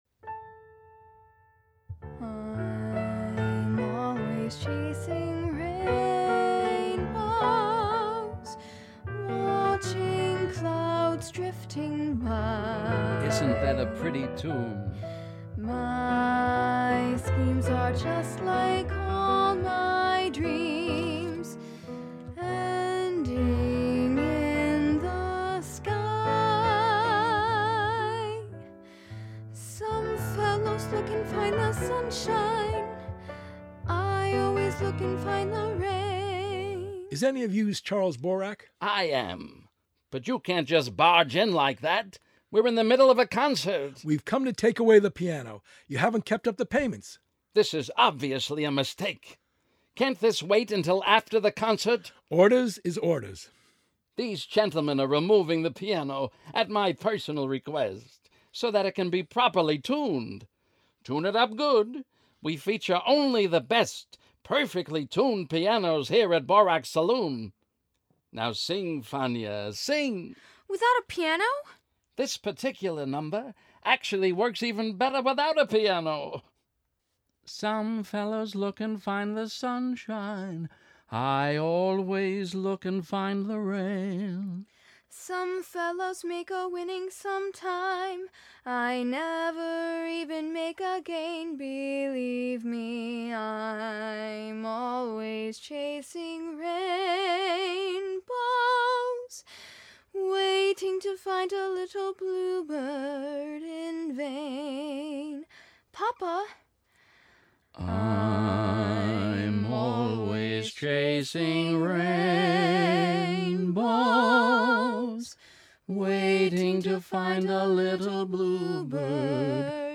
original cast album